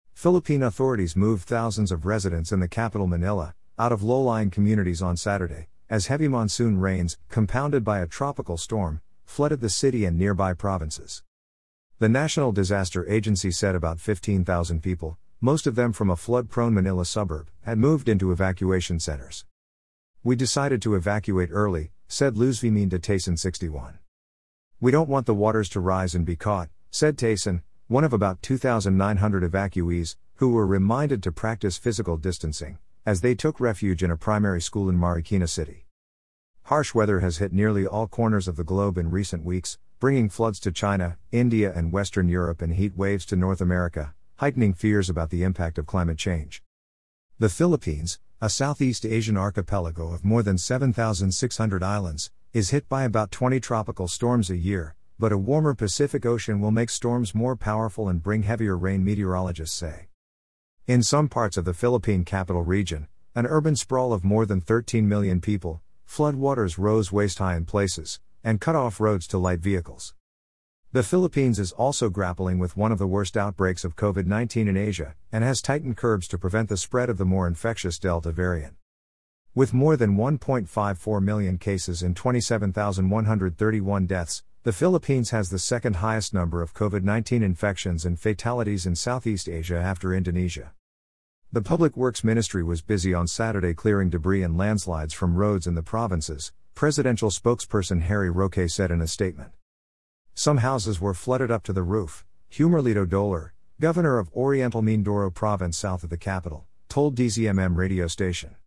Listen to this article: Philippine Coast guard evacuates thousands as days of torrential rain floods Manila